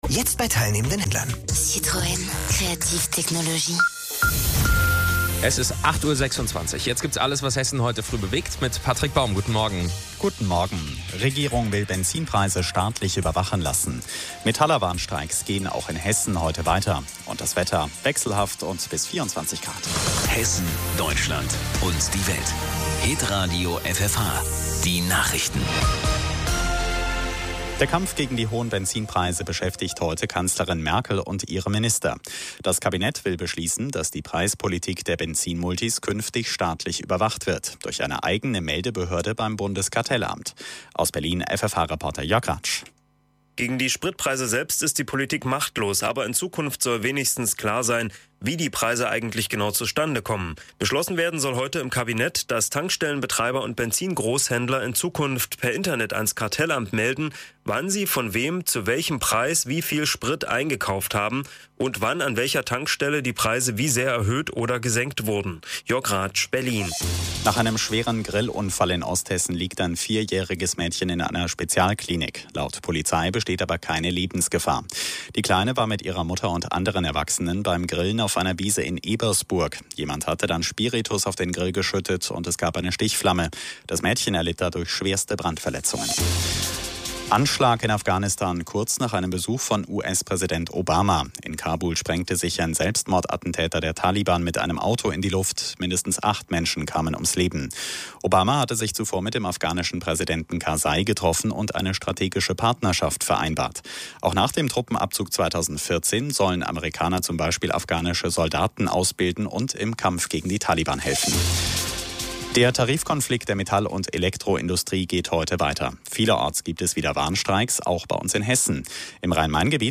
Sprechprobe: Industrie (Muttersprache):
Professional Speaker for News, OFF, E-Learning, Industrial and more...